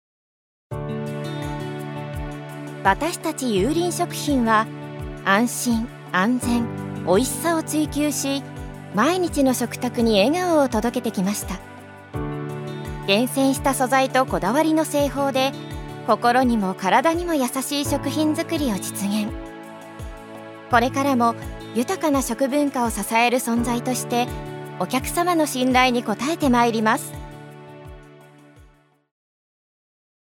女性タレント
ナレーション１